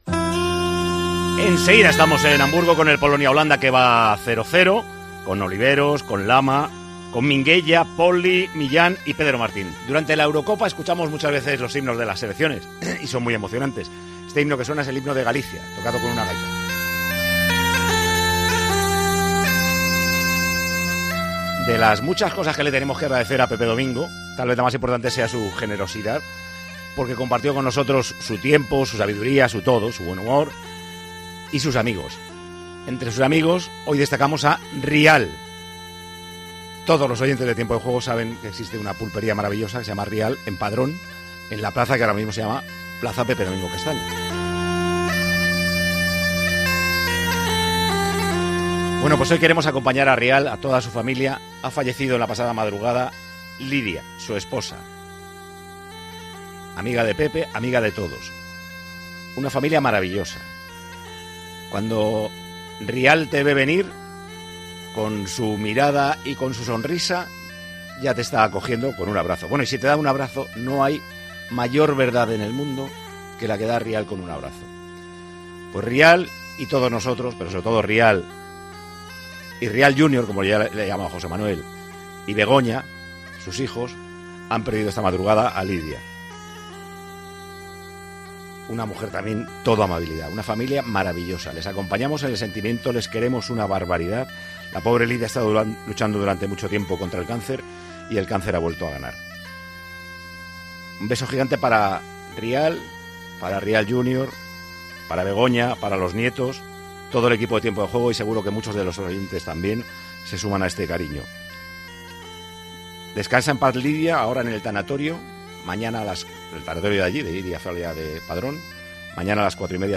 Un beso gigante para la familia, todo el equipo de Tiempo de Juego y muchos de los oyentes se unen a este cariño", apuntaba Paco González durante su discurso al comienzo del programa a la vez que sonaba el himno de Galicia.